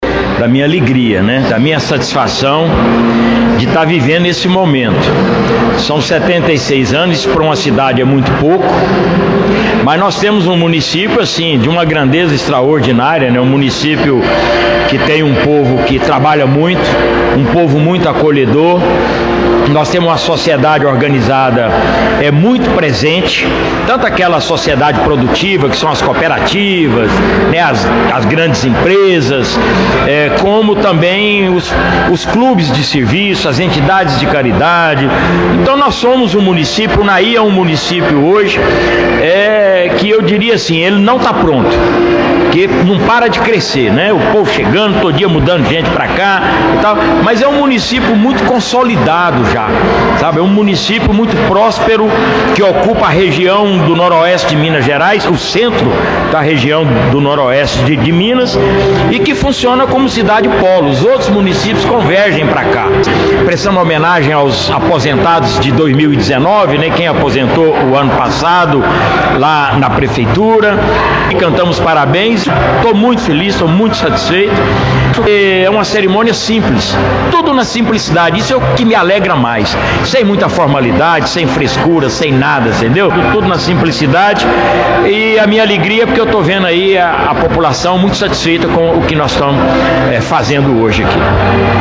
Nossa reportagem esteve presente e ouviu as autoridades que participaram ativamente das atividades.